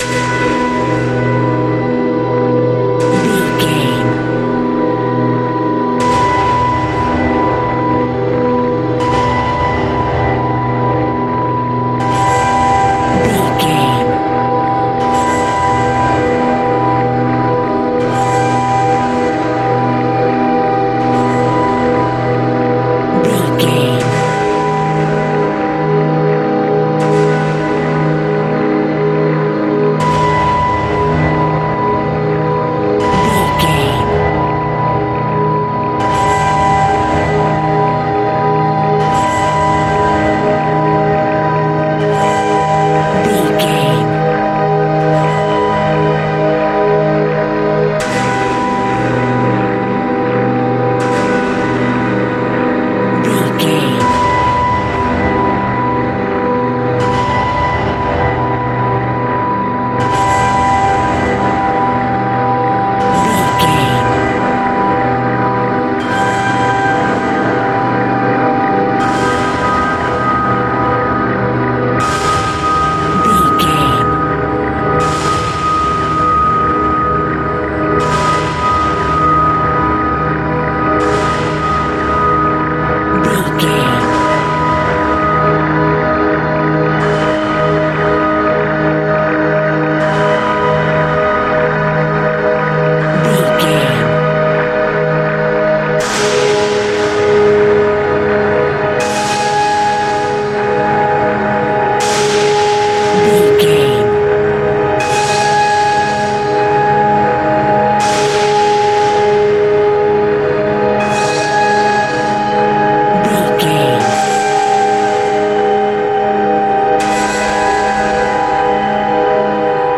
Scary and Weird Horror Atmos.
Aeolian/Minor
ominous
eerie
synthesizer
percussion
Horror Ambience
Synth Pads
Synth Ambience